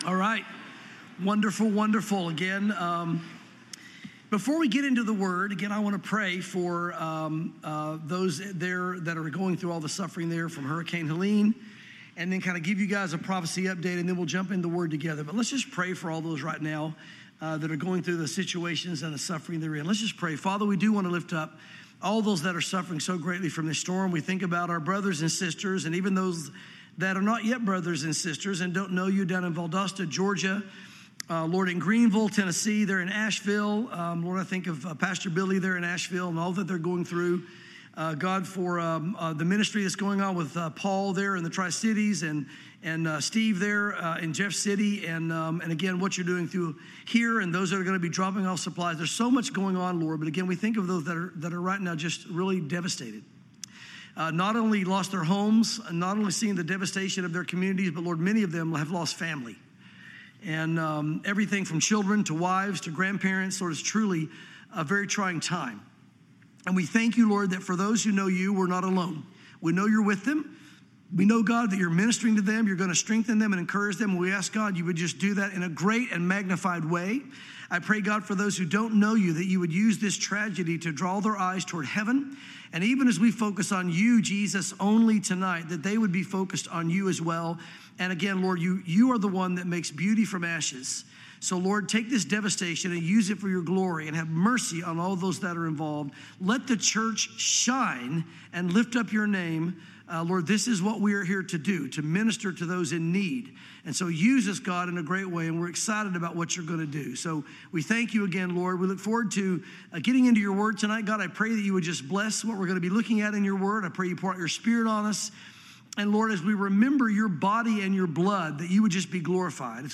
Welcome to Calvary Chapel Knoxville!